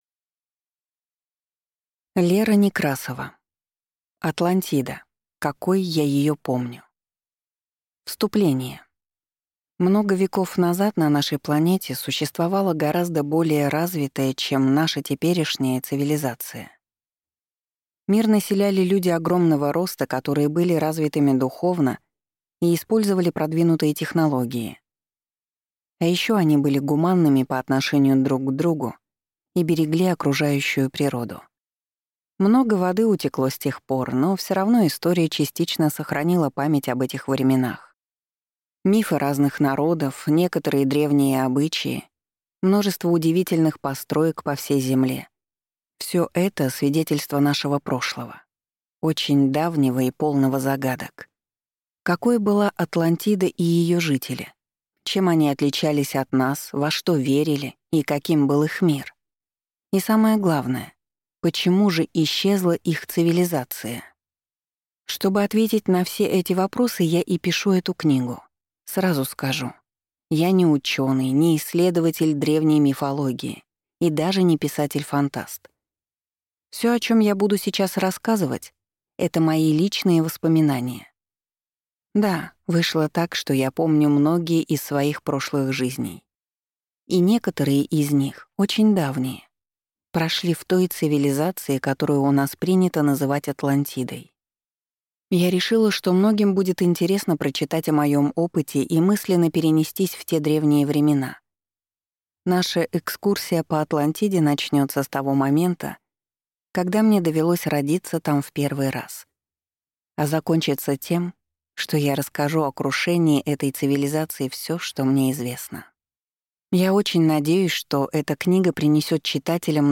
Аудиокнига Атлантида, какой я ее помню… Часть 1 | Библиотека аудиокниг